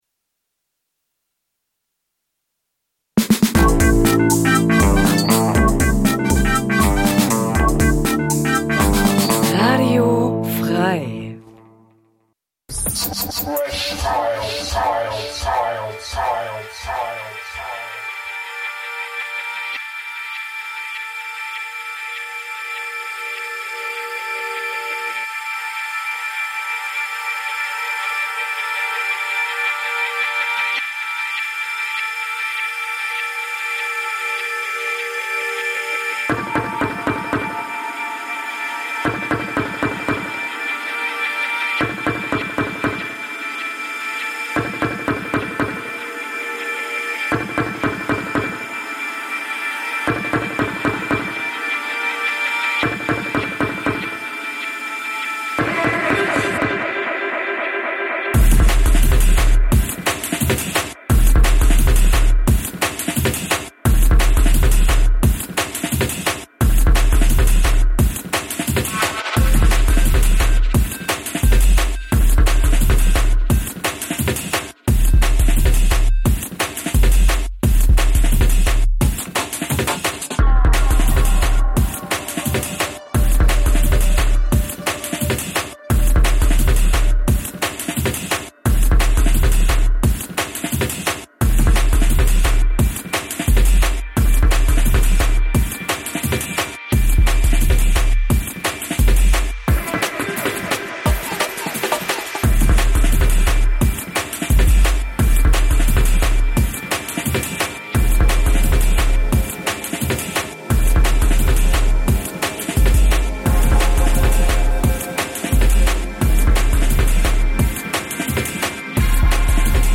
Electronic Music Dein Browser kann kein HTML5-Audio.
In der Sendung werden Neuerscheinungen aus den Bereichen Techno, House und Drum'n'Bass vorgestellt.